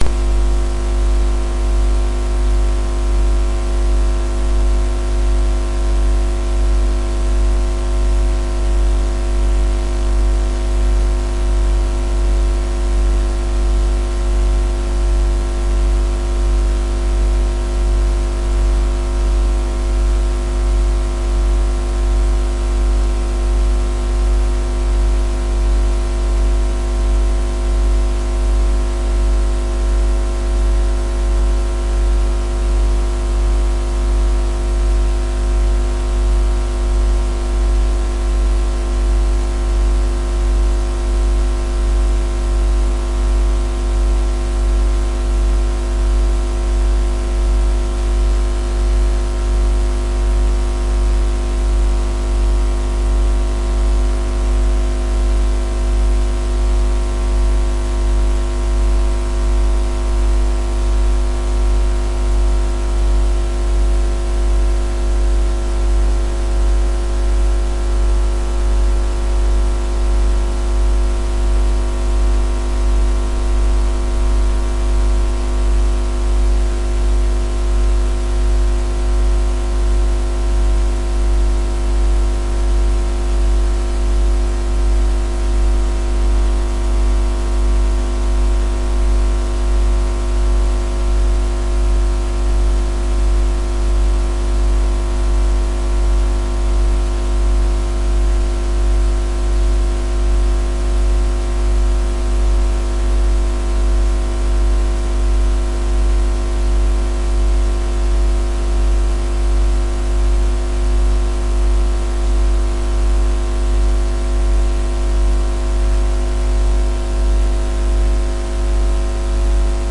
描述：助推器驾驶检查器
Tag: 起动器 ECU 发动机